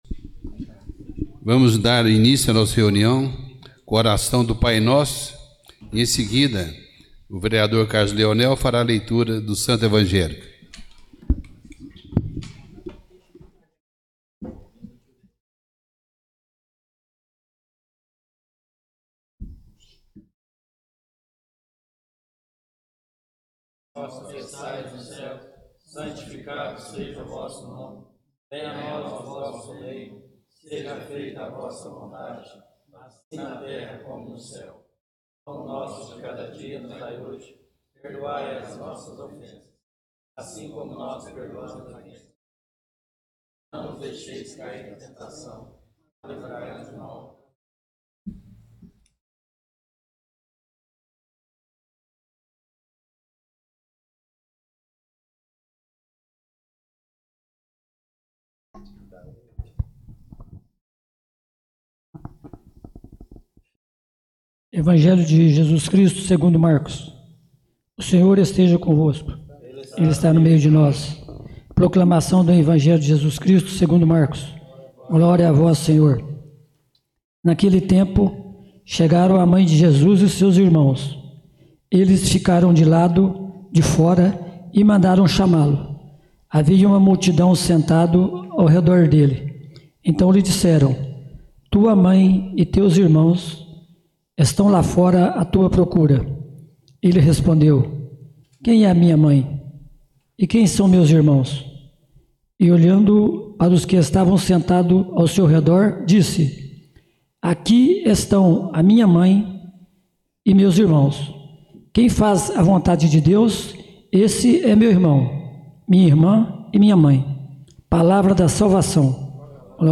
Por haver número legal, sob a proteção do nosso Senhor Jesus Cristo, de onde emana toda força e poder, declaro aberta a 1ª Sessão Extraordinária neste dia 24 de janeiro de 2023.
Tipo de Sessão: Extraordinária